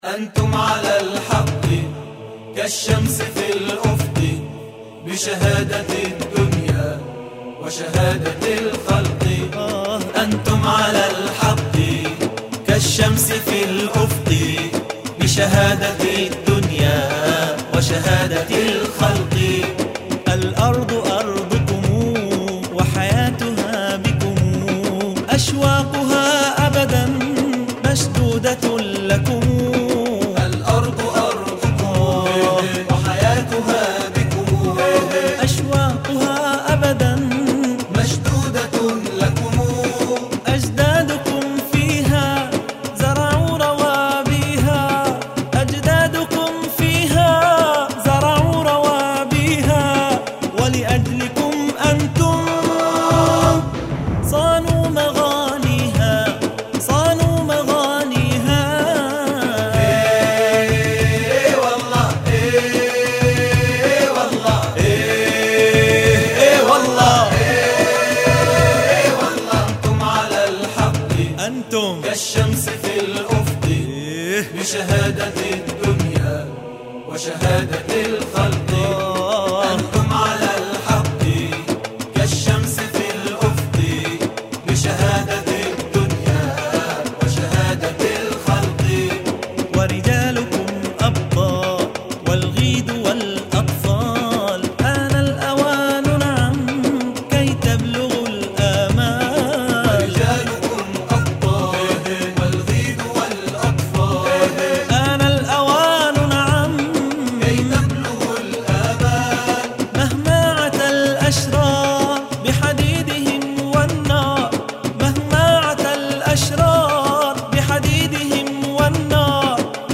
أناشيد فلسطينية